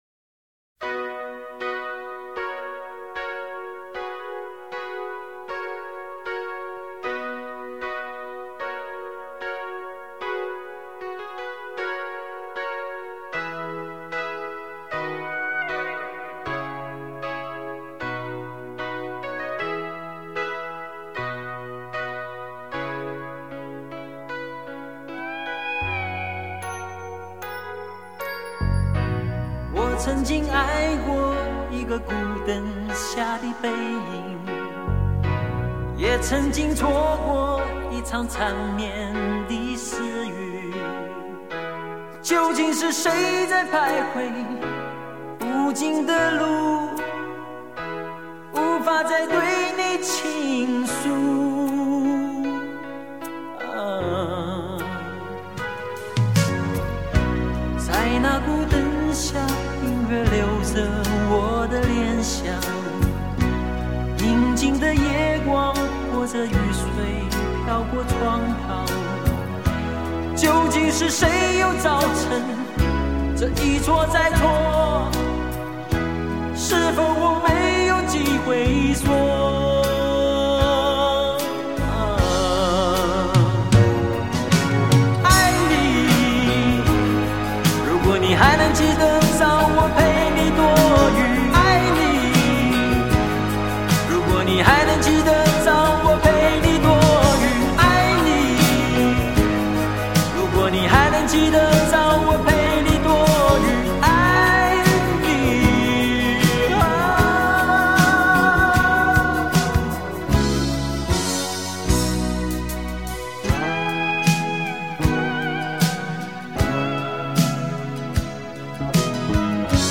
延续了当年台湾校园歌曲的风格，而独有的抒情浪漫在当时的港台歌坛也是少有的。
悦耳流畅的钢琴前奏很容易的让人回到初恋的年代。